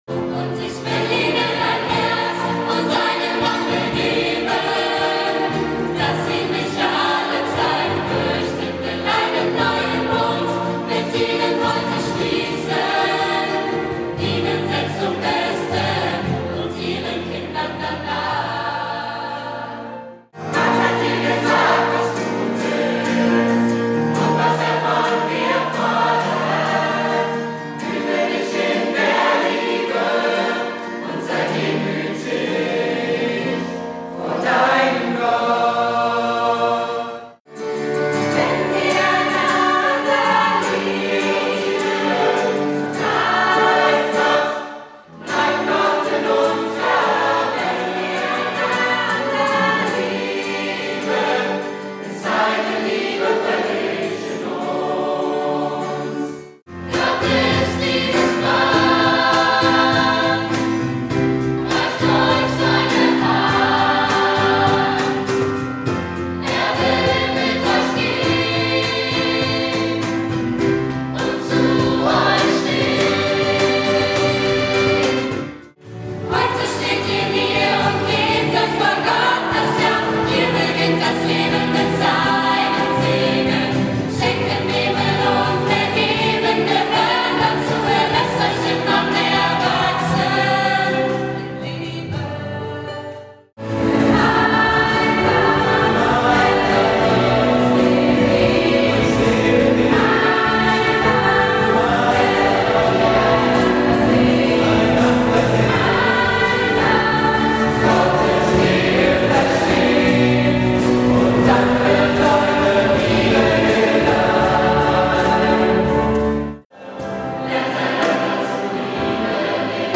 12 Lieder speziell für Chöre geschrieben.